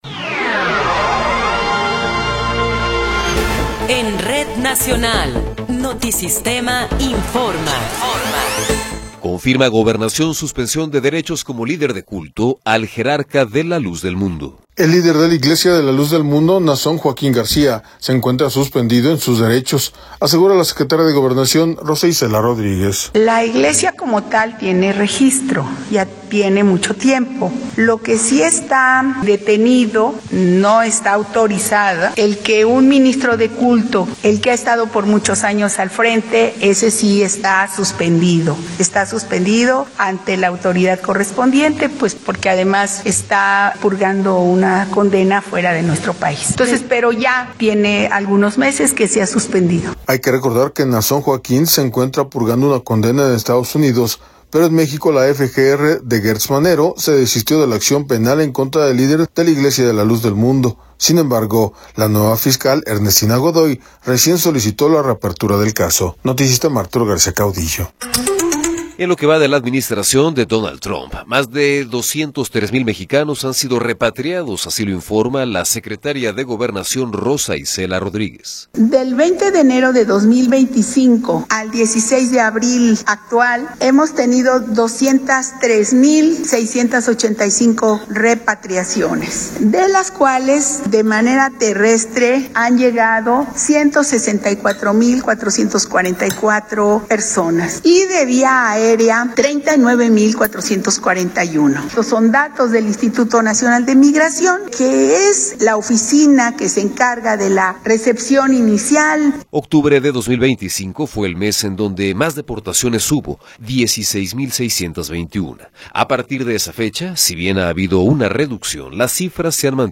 Noticiero 12 hrs. – 17 de Abril de 2026